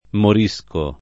[ mor &S ko ]